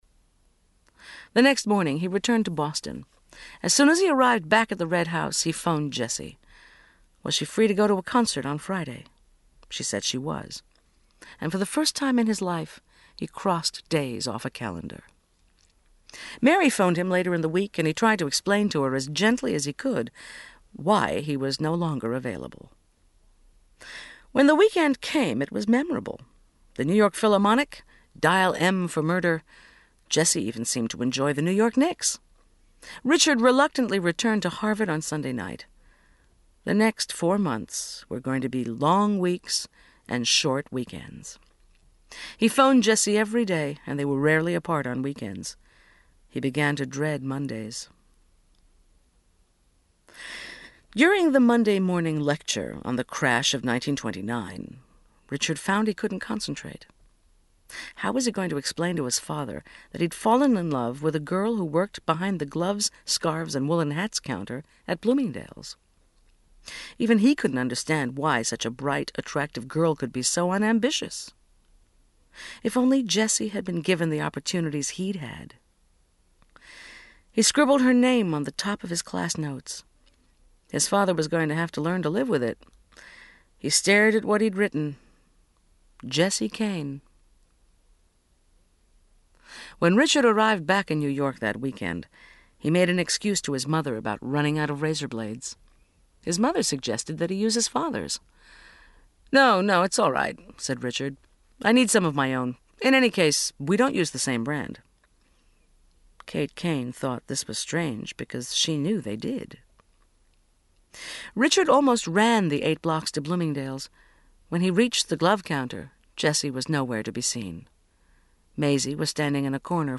37serv Audiobooks/Jeffrey Archer - Prodigal Daughter (1982) (96)